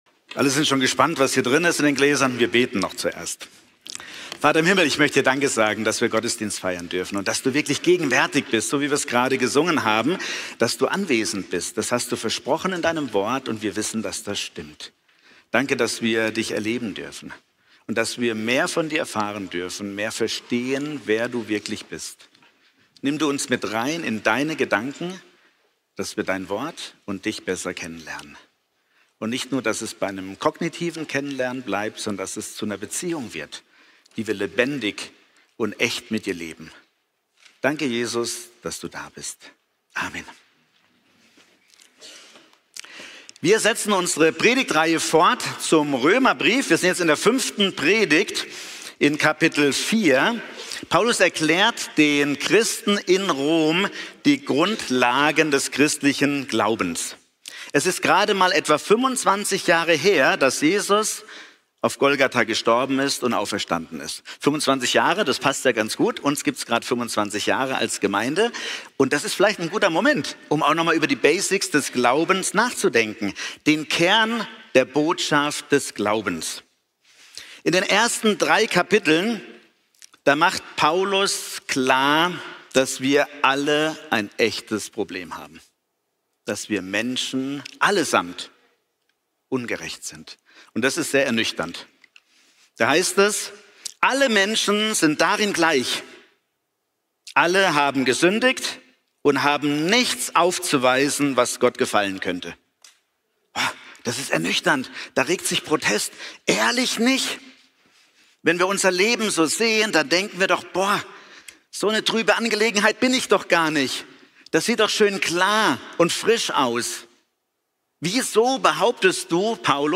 Römer 4 Typ: Predigt Man klebt kein Pflaster auf einen Krebs